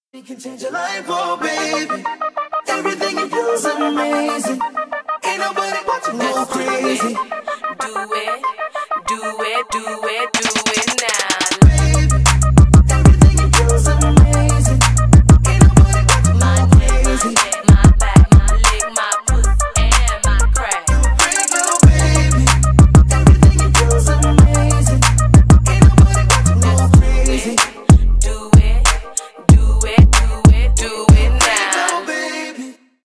Электроника
клубные